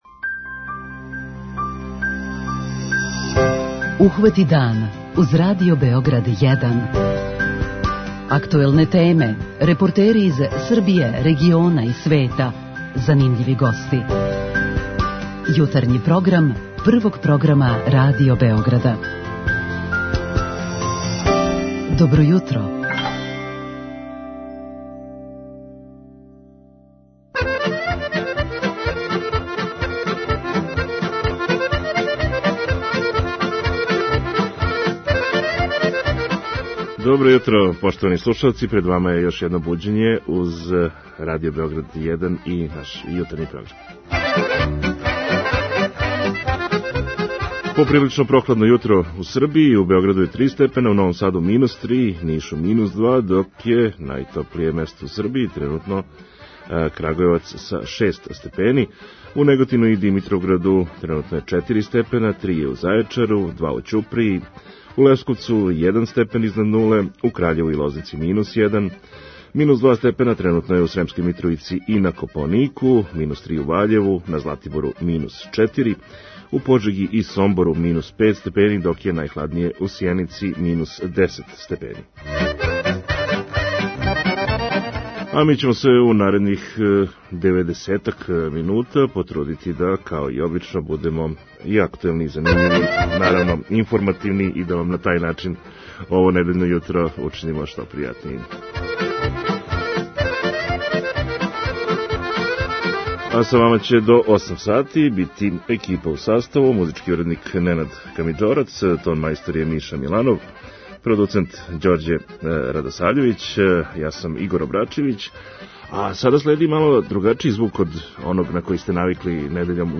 Поводом Светског дана радија, чија је тема ове године Радио и поверење, у јутарњем програму Ухвати дан разговараћемо са нашим сталним сарадницима из АМСС-а и РХМЗ-а и члановима наше спортске редакције.